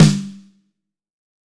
Index of /90_sSampleCDs/AKAI S-Series CD-ROM Sound Library VOL-3/ROCK KIT#1